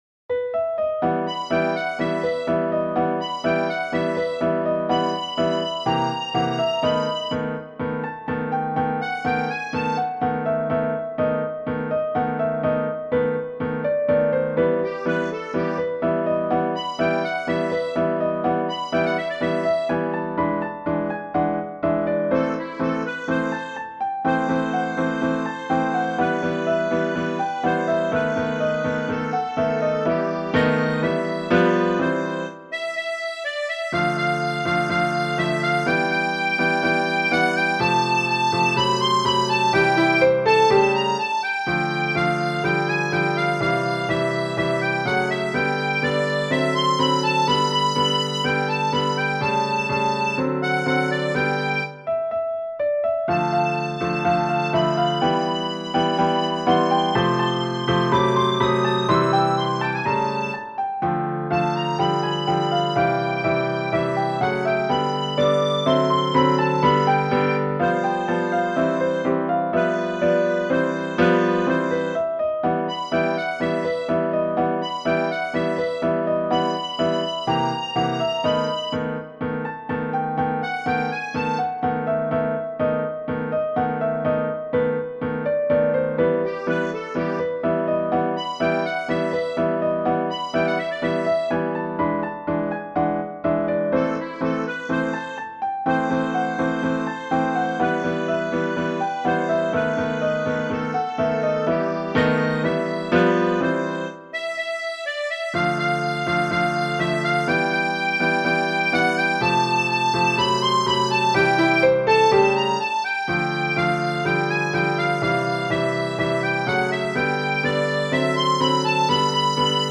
Genere: Ballabili
Volviò una Noche" è un tango del 1935